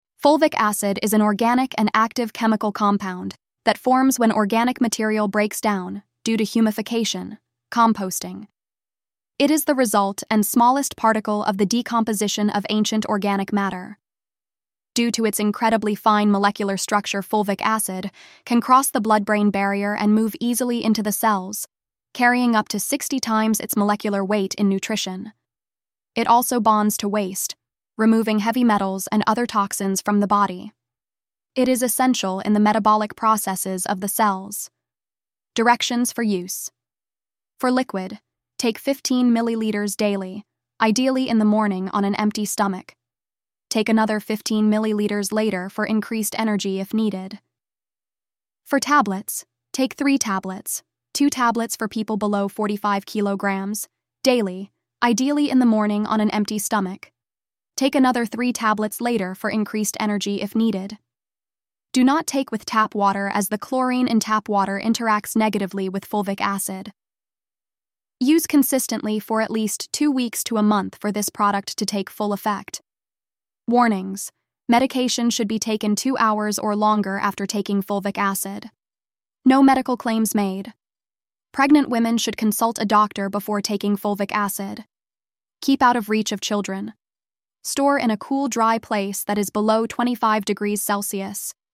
Narrated Product Information.
Oshun-Health-Fulvic-Acid-Voiceover.mp3